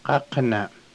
qaqlwlp̓   [qáqəlwəlp̓] Morph: qa•qlwlp̓.